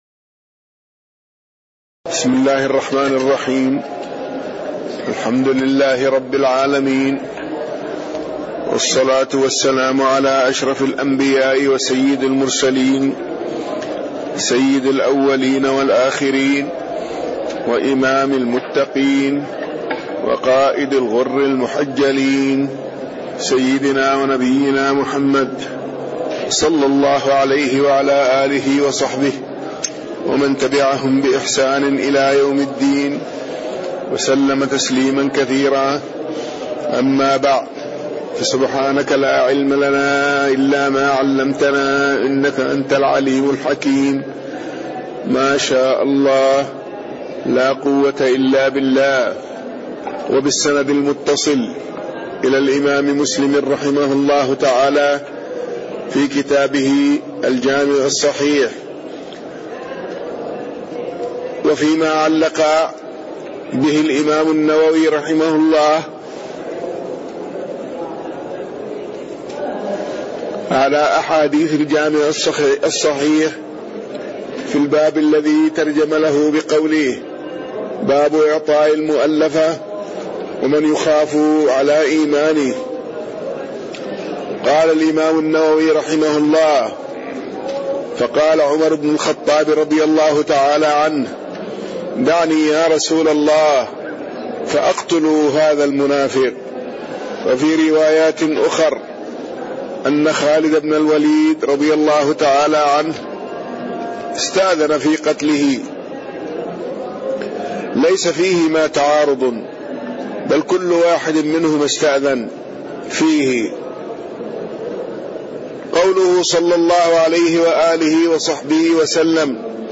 تاريخ النشر ١٨ محرم ١٤٣٣ هـ المكان: المسجد النبوي الشيخ